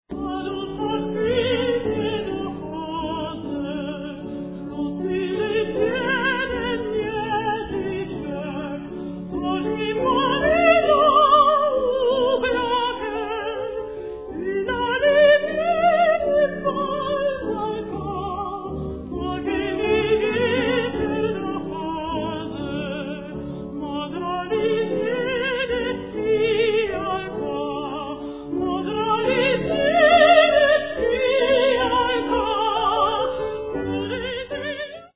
mezzo soprano
bass baritone
piano